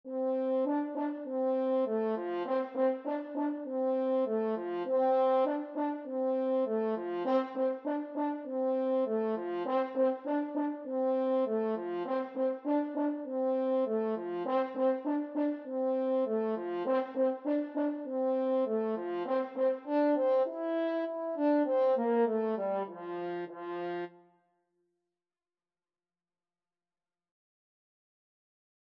Allegro moderato (View more music marked Allegro)
F major (Sounding Pitch) C major (French Horn in F) (View more F major Music for French Horn )
4/4 (View more 4/4 Music)
F4-E5
Beginners Level: Recommended for Beginners
French Horn  (View more Beginners French Horn Music)
Classical (View more Classical French Horn Music)